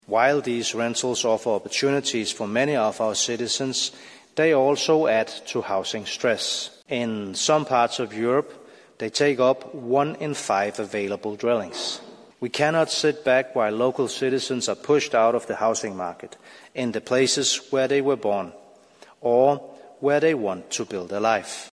EU Commissioner Dan Jorgensen says there’s a need to ensure the best use of existing homes: